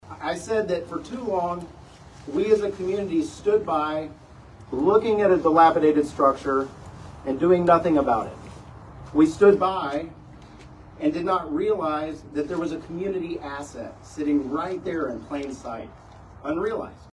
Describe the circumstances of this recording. Manhattan Area Habitat for Humanity holds ribbon cutting ceremony for local family